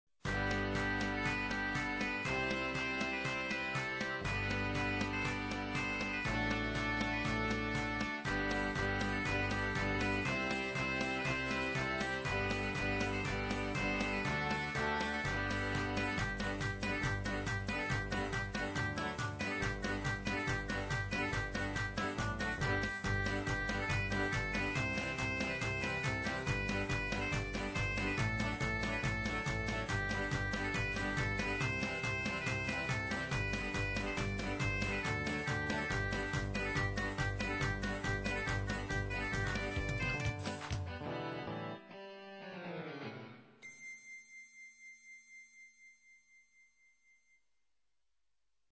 Everything is made in MIDI which gives a thinner sound.
[instr.]